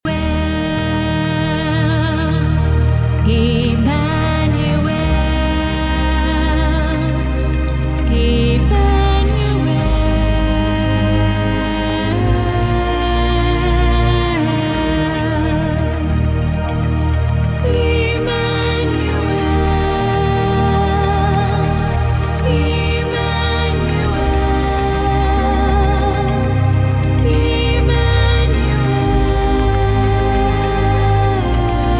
soaring vocals blend in beautiful harmonies